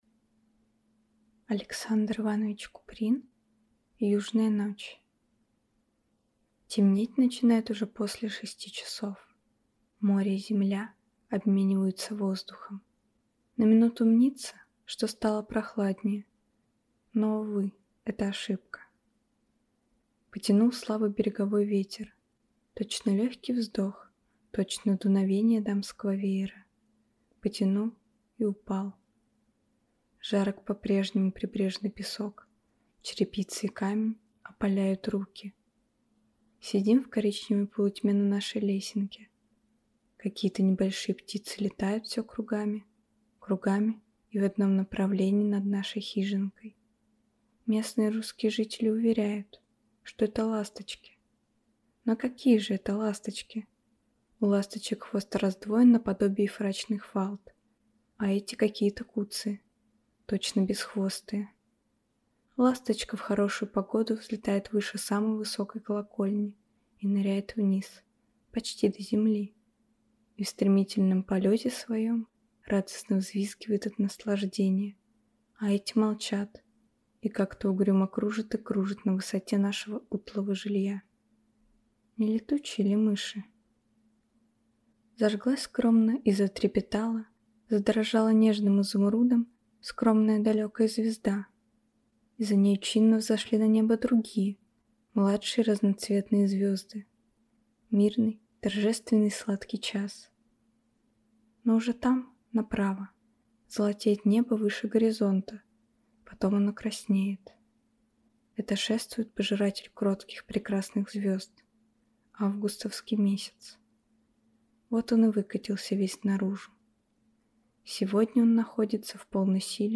Аудиокнига Южная ночь | Библиотека аудиокниг